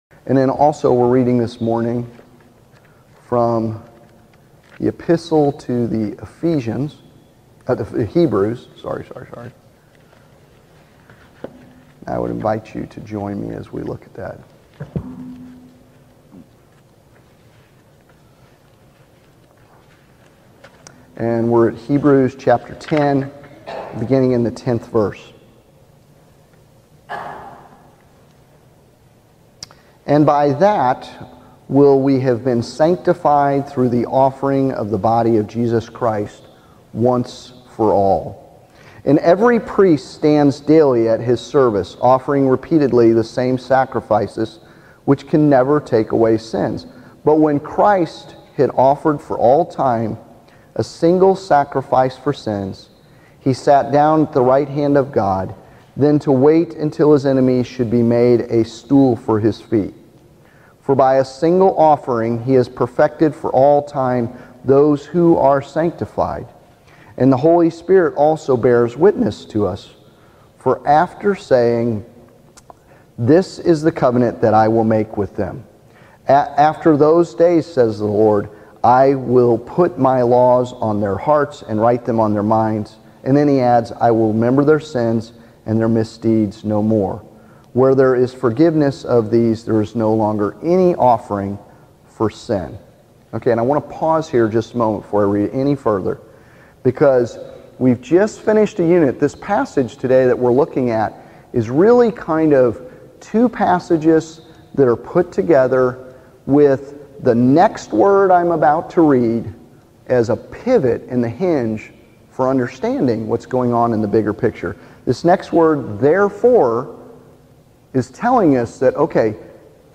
SERMON: Four Ways to Live a Sanctified Life